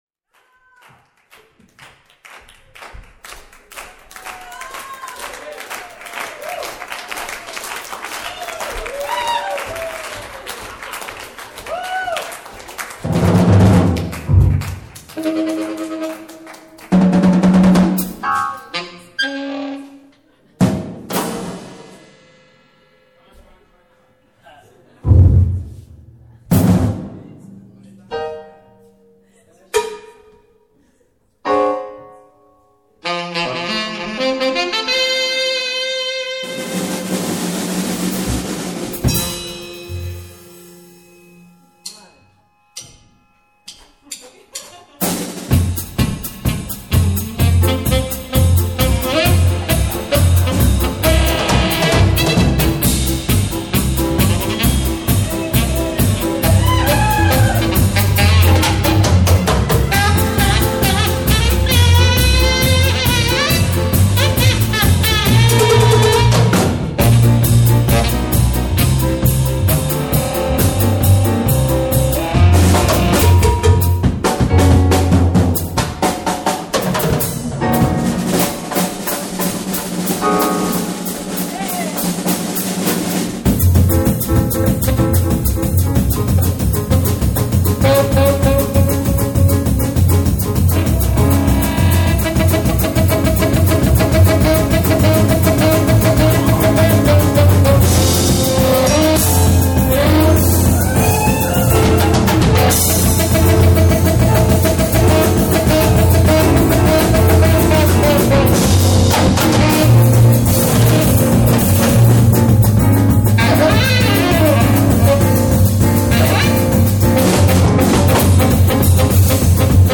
Drums, Balaphons, Gongs, Tuned Disks
Tenor, Alto & Sopran Sax
Piano, Synthesizer, Left Hand Bass
Here is the music; unfortunately the original recording was a bit distorted which cannot be repaired in the mastering process; but anyway it’s a nice souvenir.
(volkstümmliche imrpo)